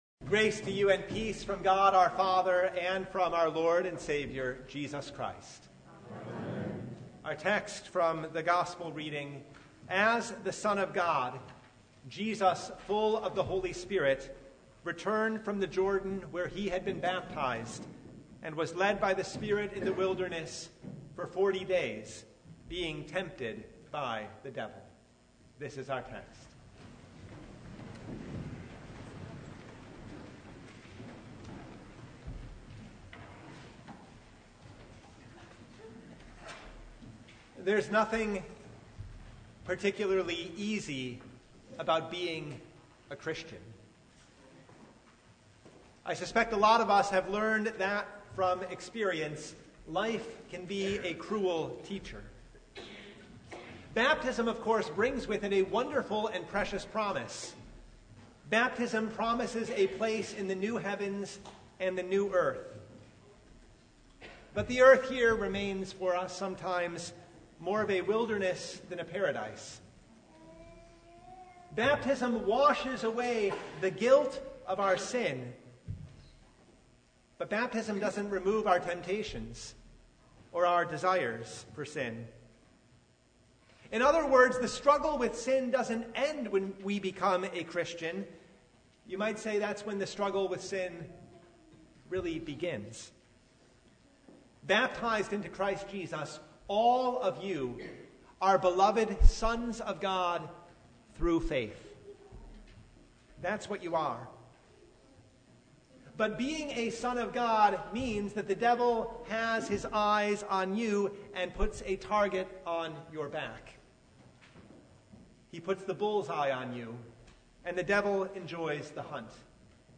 Luke 4:1–13 Service Type: Sunday Into temptation.